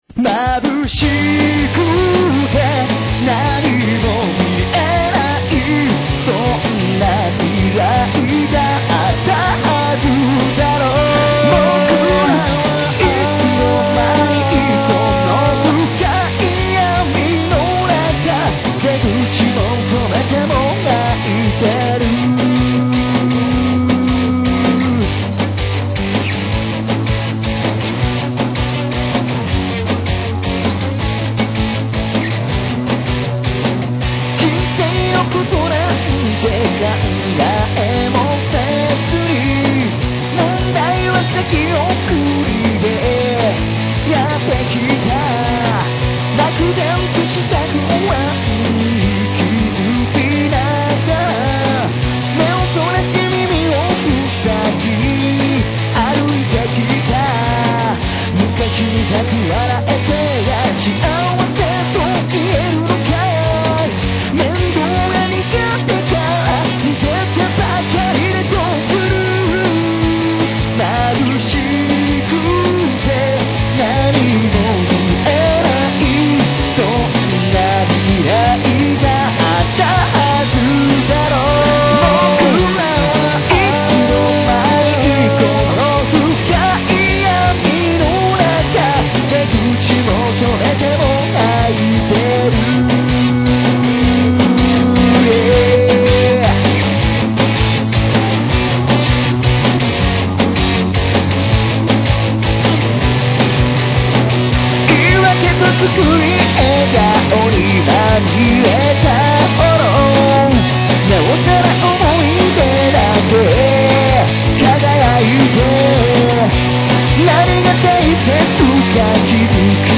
ちなみに、音源は自宅で録音。ドラムはずーっと同じビート。